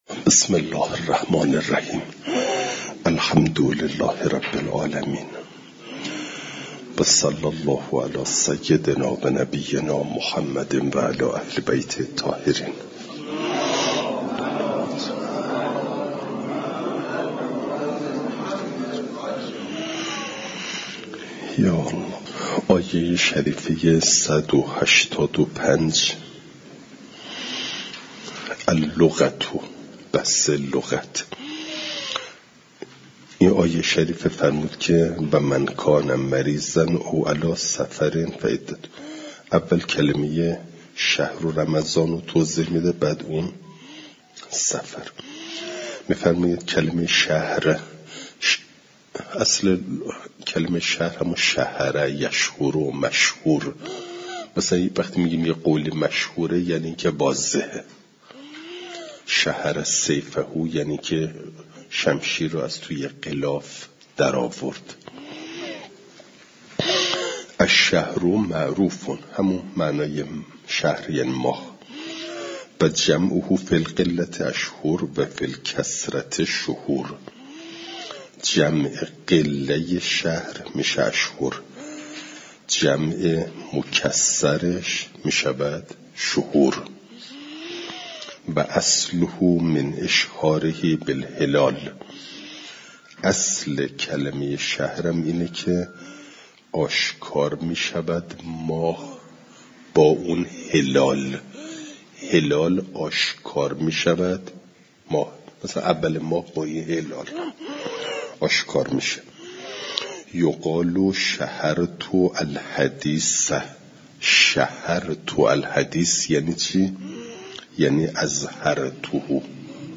فایل صوتی جلسه صد و هشتاد و دوم درس تفسیر مجمع البیان